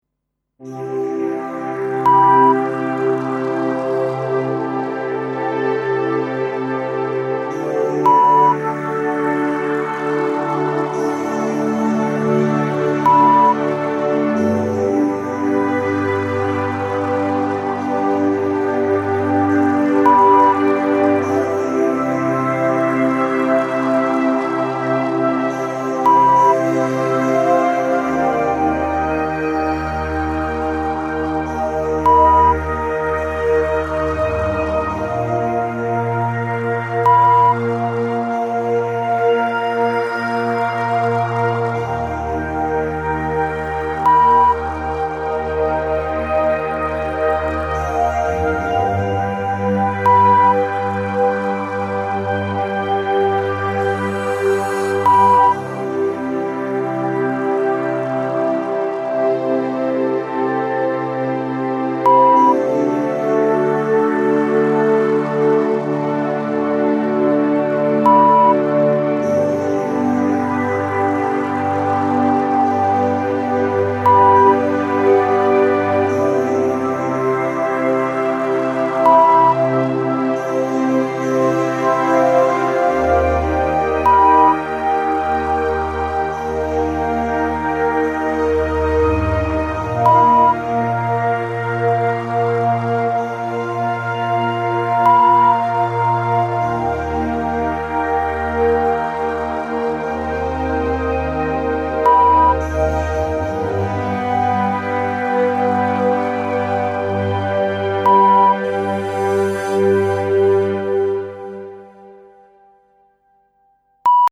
Die Hörprobe enthält Störtöne.
♫ Tempo: langsam (70 bpm)